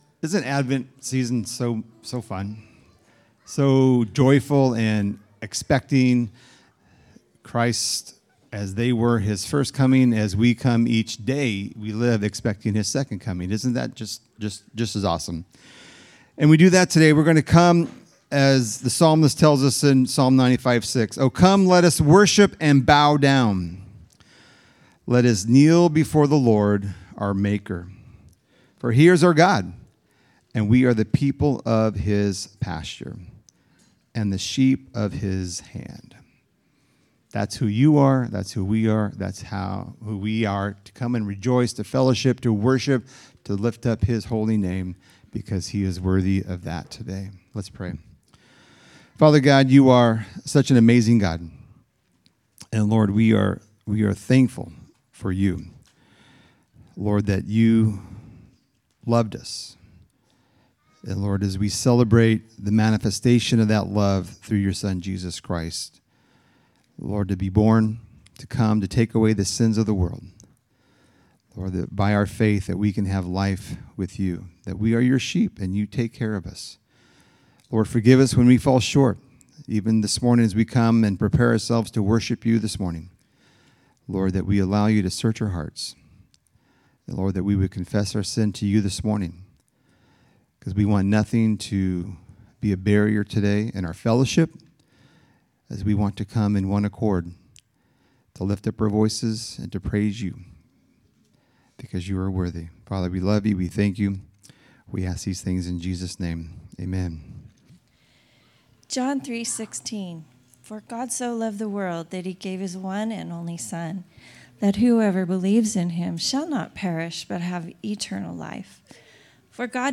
For the second week of Advent we light the Bethlehem Candle, or the Candle of Love.